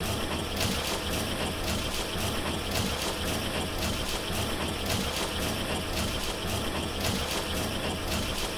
assemblerOperate.ogg